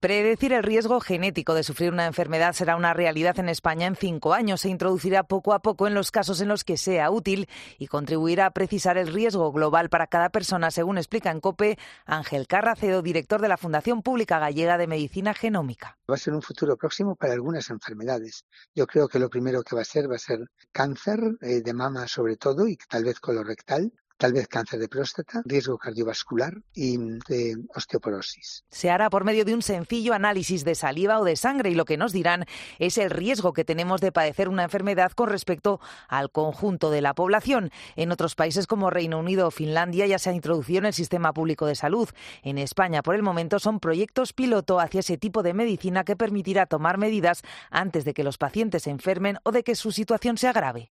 En cinco años será posible predecir la aparición de enfermedades en España. Crónica